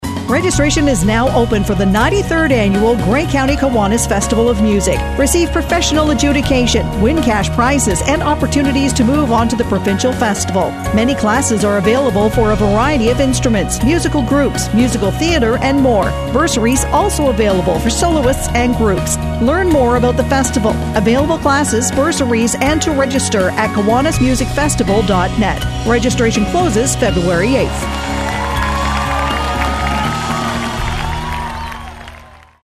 Bayshore-ad-GCK-Festival-OF-MUSIC-JAN-2025.mp3